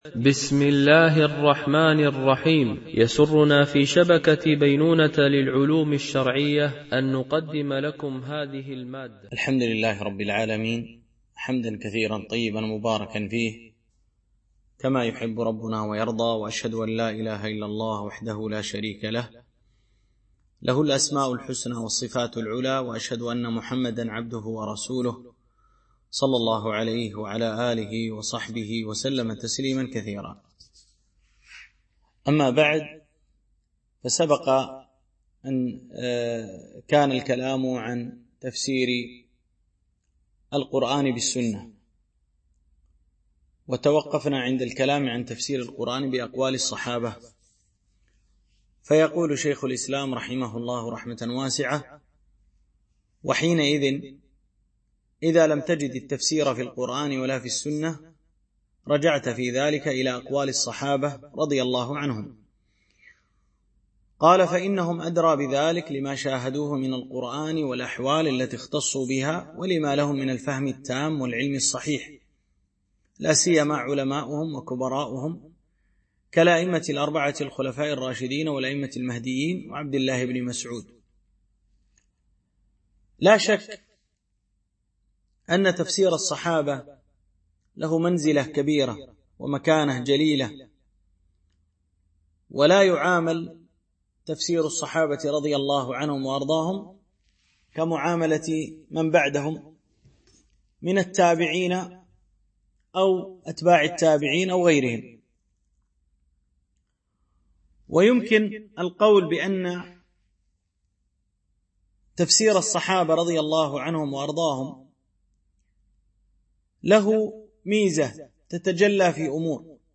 شرح مقدمة في أصول التفسير ـ الدرس 11